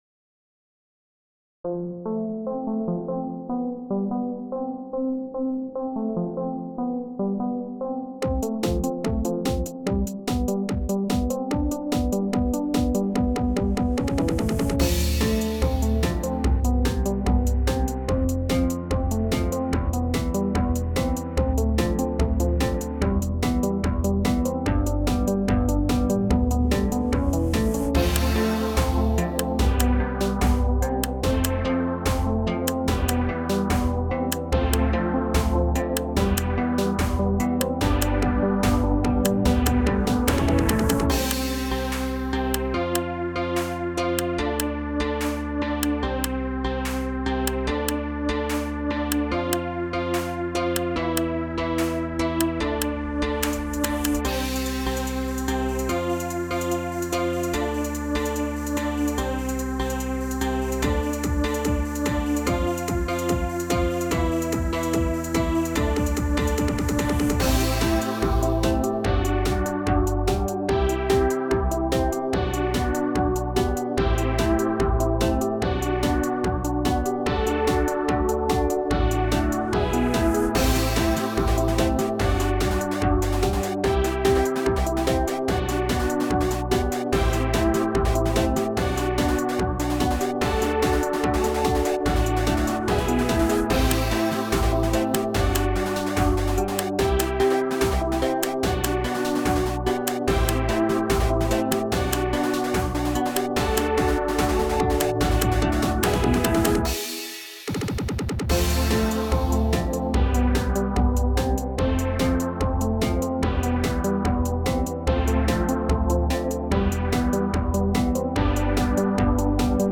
SUMMER TECHNO POP
聴いてて不安になるものになってしまいました。